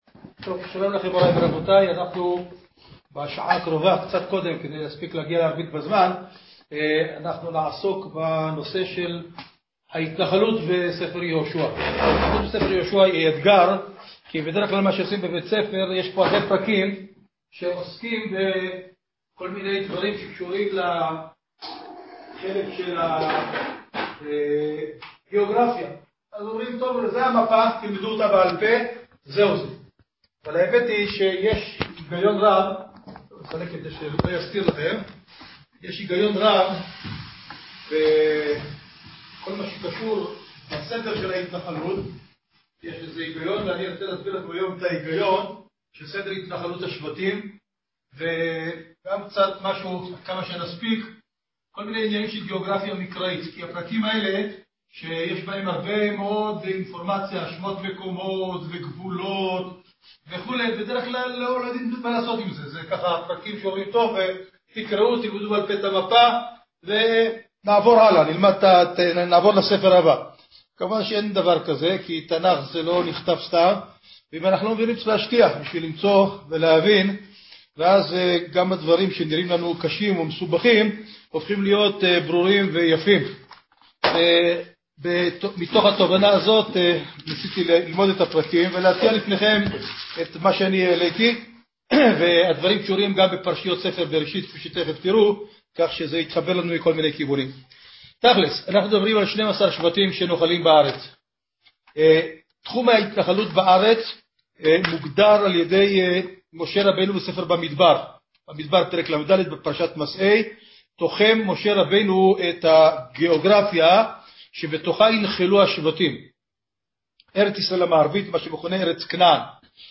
סדר התנחלות השבטים: מצורפת הקלטה משיעור